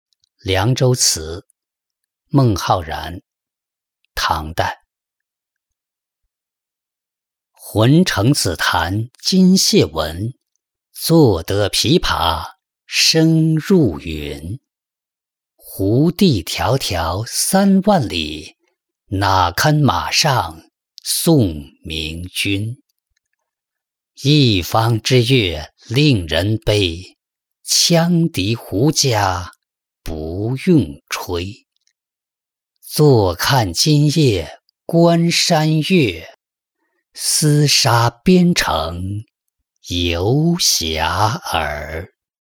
凉州词-音频朗读